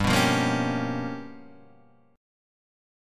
G 7th Sharp 9th Flat 5th